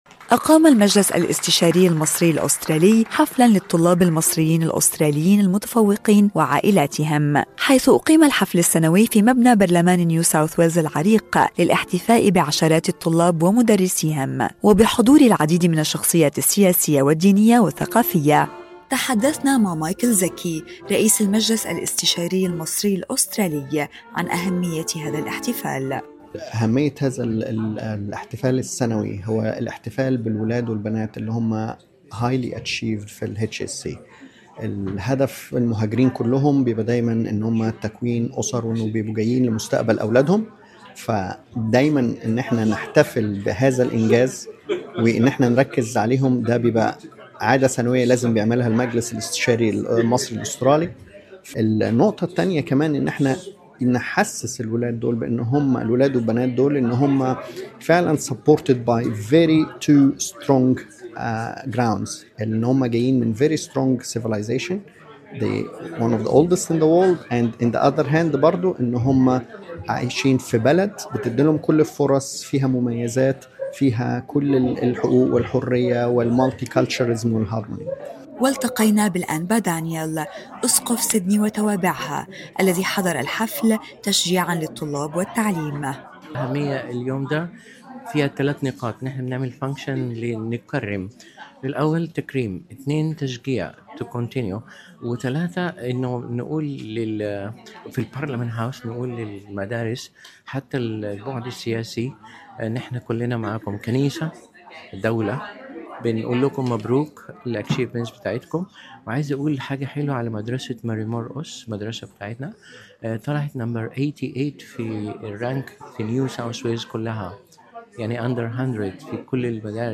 في أجواء احتفالية داخل مبنى برلمان نيو ساوث ويلز، كرّم المجلس الاستشاري المصري الأسترالي عشرات الطلاب المصريين الأستراليين المتفوقين في الثانوية العامة، بحضور شخصيات سياسية ودينية ودبلوماسية. الحفل لم يكن مجرد احتفاء بنتائج أكاديمية، بل رسالة تقدير لأبناء المهاجرين الذين جسّدوا حلم عائلاتهم في مستقبل أفضل، ورسالة دعم لجيل يُنظر إليه كجسرٍ حيوي يربط بين مصر وأستراليا.